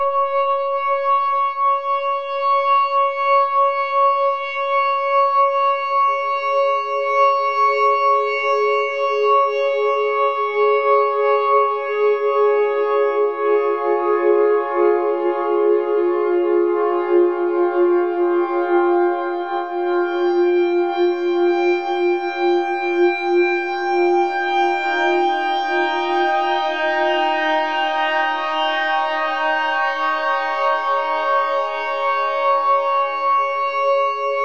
C# LYDIAN.wav